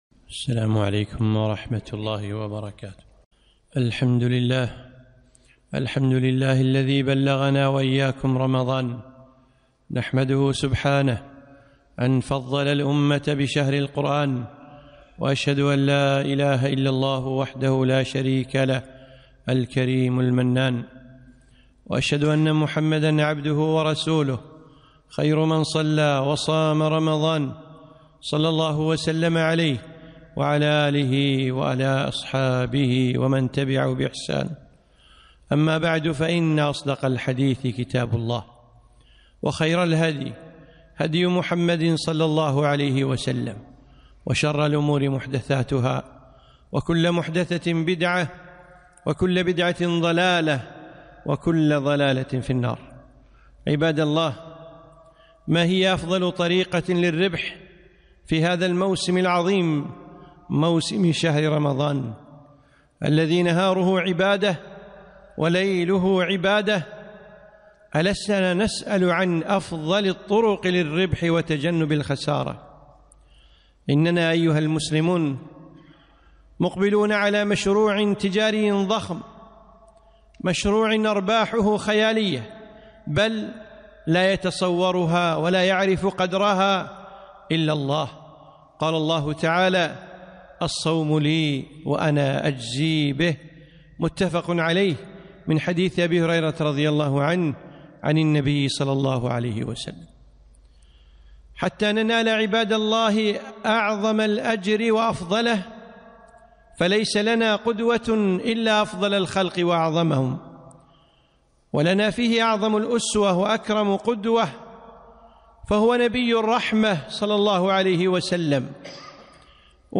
خطبة - كيف كان رسول الله ﷺ في رمضان؟